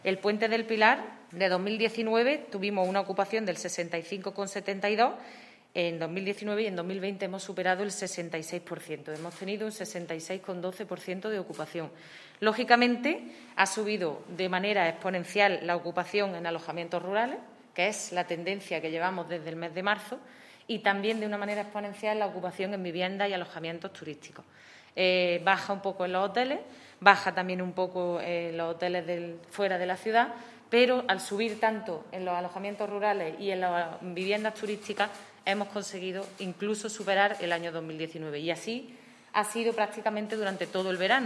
Así lo confirmaba ayer en rueda de prensa la teniente de alcalde Ana Cebrián, quien junto con el alcalde Manolo Barón y el primer teniente de alcalde Juan Rosas informaron sobre medidas que comenzará a adoptar el Ayuntamiento de forma inmediata para tratar de frenar la progresión generalizada de los contagios por el coronavirus SARS-COV-2, causante de la COVID-19.
Cortes de voz